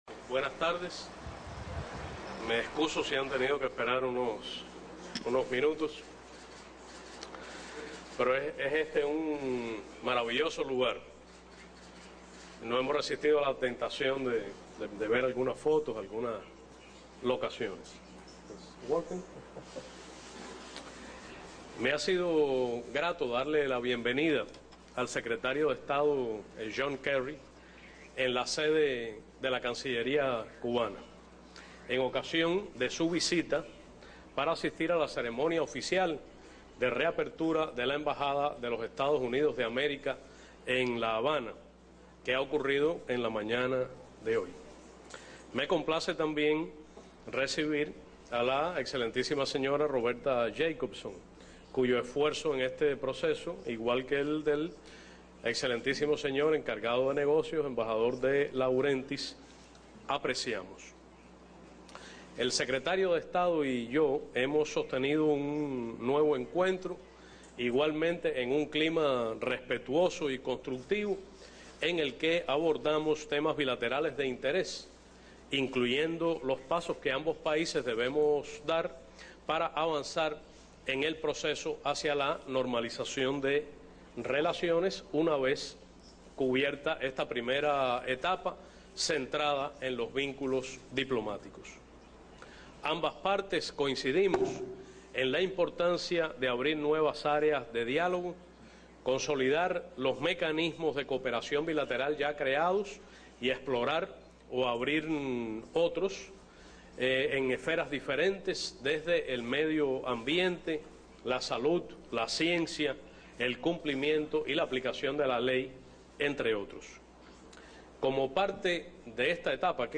Palabras del Ministro de Relaciones Exteriores de Cuba, Bruno Rodríguez Parrilla, en la conferencia de prensa luego de la reapertura oficial de la embajada de Estados Unidos en Cuba.
Bruno_conf.mp3